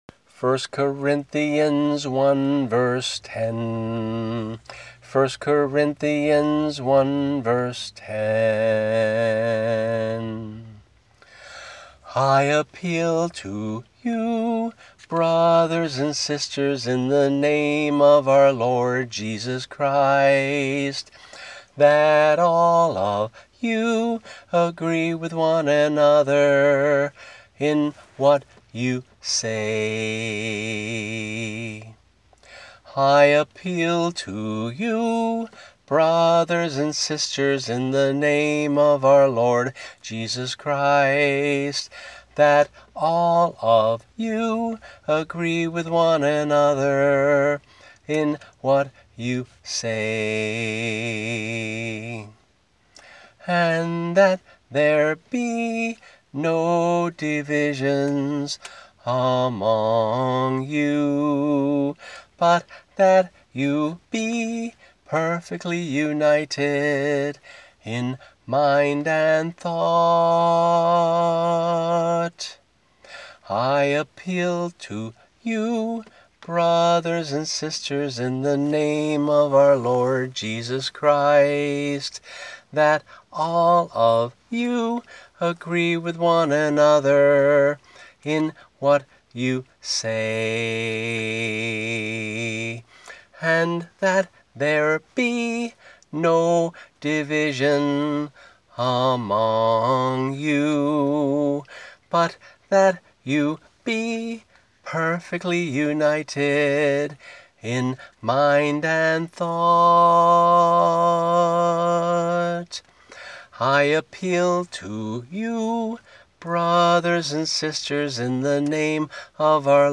[MP3 - only vocal]